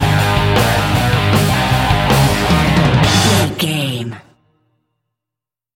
Aeolian/Minor
A♭
Fast
drums
electric guitar
pop rock
hard rock
lead guitar
bass
aggressive
energetic
intense
powerful
nu metal
alternative metal